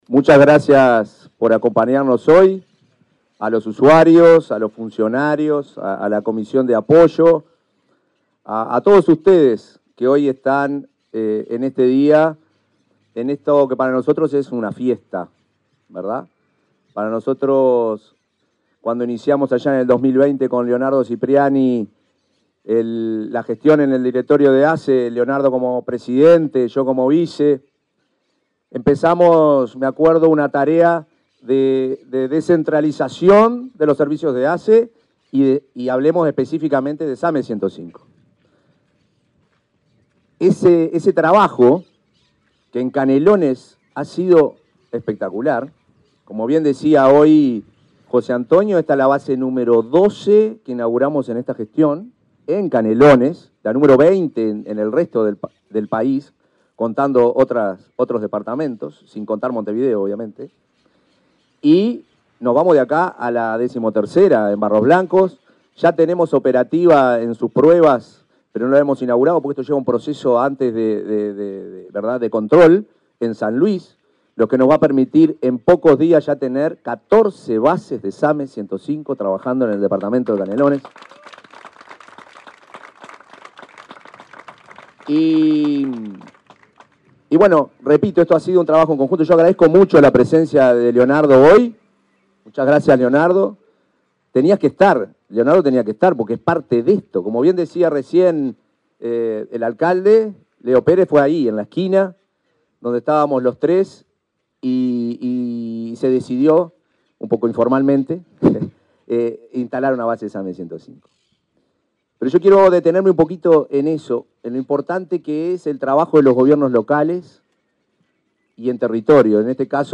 Palabras del presidente de ASSE en Tala, Canelones